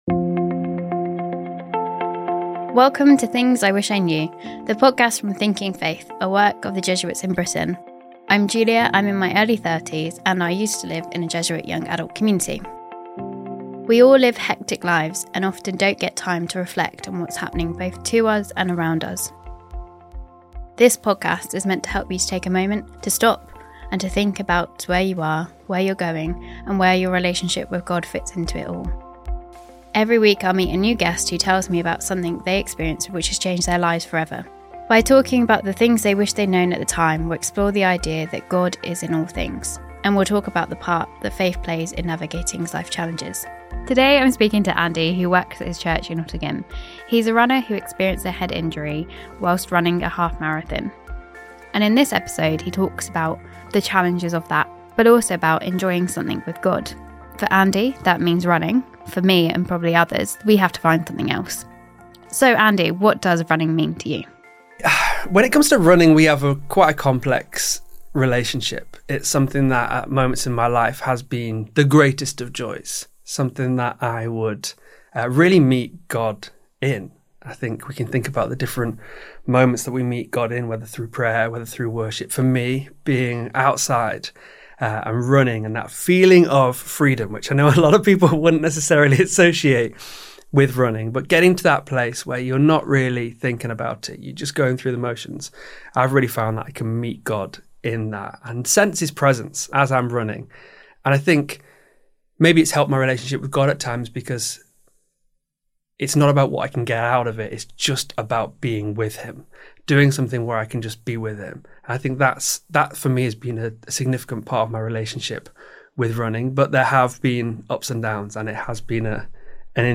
… continue reading 10 episodes # Society # Religion # Conversations # Christianity # Audioboom # Thinking Faith